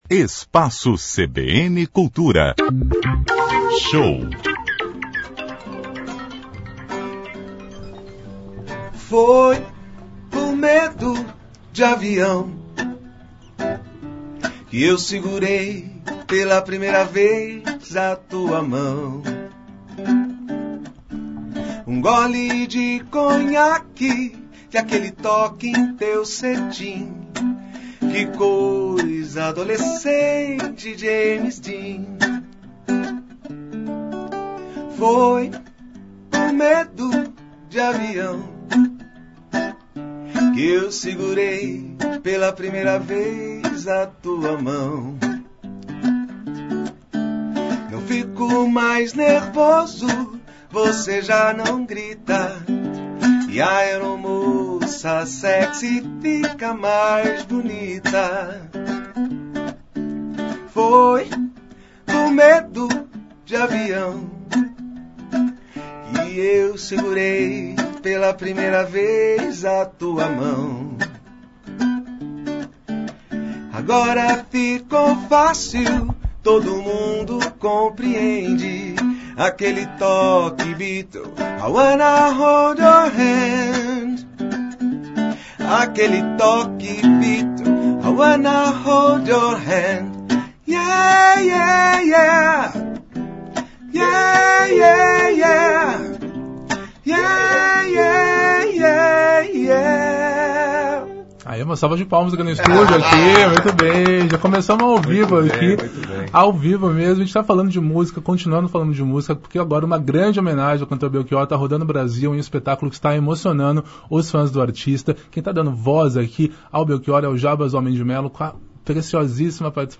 Entrevista: Jarbas Homem de Mello e banda Radar ativam o nostalgismo ao homenagear Belchior - CBN Campinas 99,1 FM